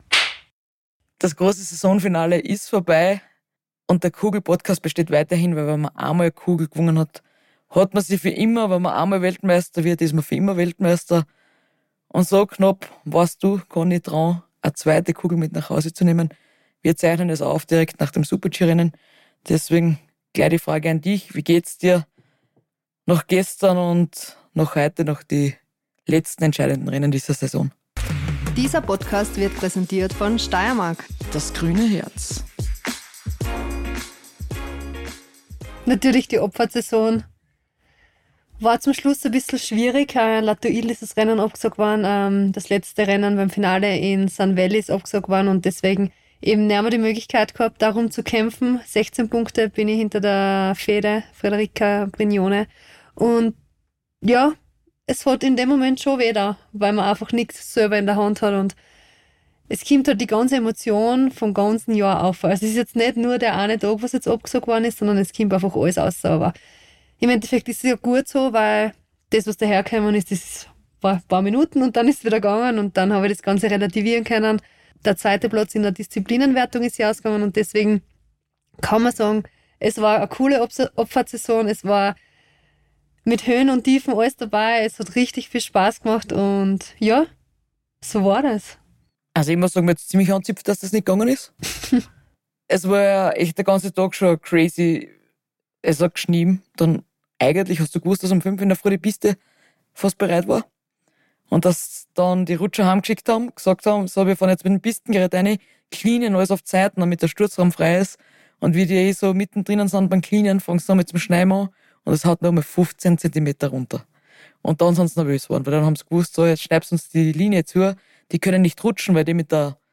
Wir, Nici Schmidhofer und Conny Hütter sind professionelle Skirennläuferinnen und wollen euch in Situationen reinhören lassen, die bisher von der Öffentlichkeit nicht belauscht werden konnten. Unser Podcast gibt akustische Einblicke etwa in eine Streckenbesichtigung, in unser Gespräch mit dem Servicemann im Skiraum oder auch mal in jene Themen, die wir Mädels vor dem Rennen so besprechen.